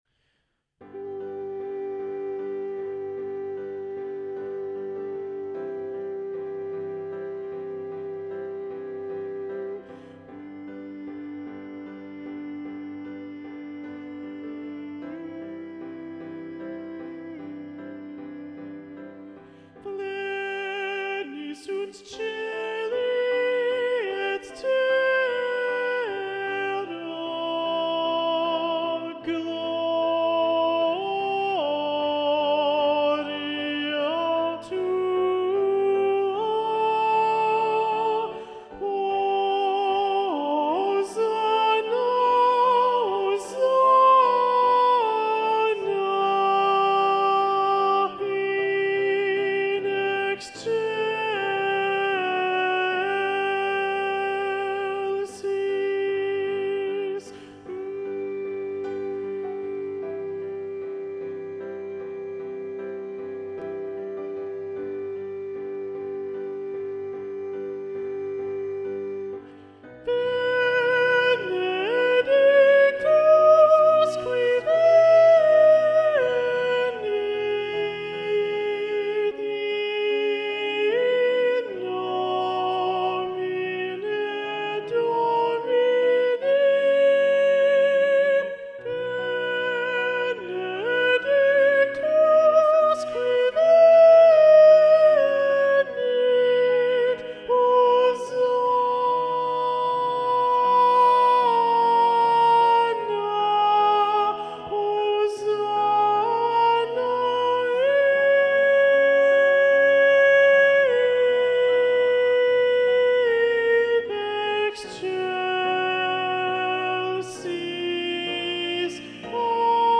Soprano 2
The-Ground-Soprano-2-Predominant-Ola-Gjeilo.mp3